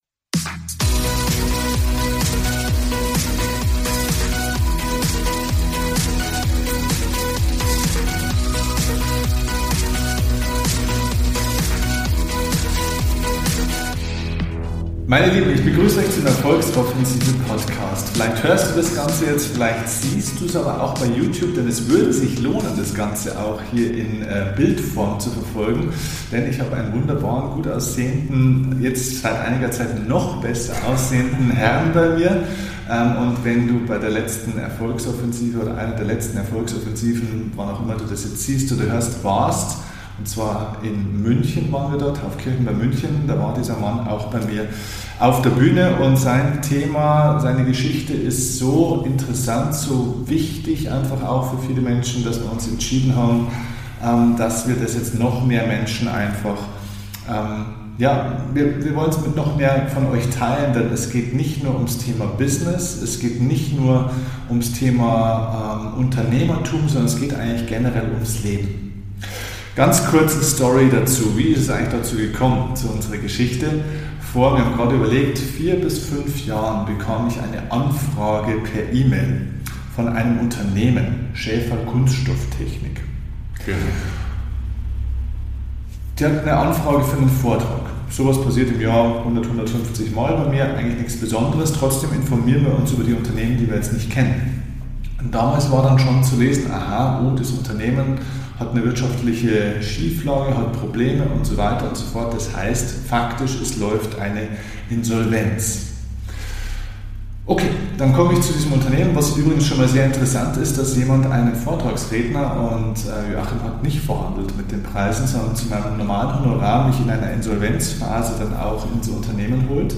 Die Erfolgsinsolvenz – Vom Schuldenberg zum Durchbruch – Interview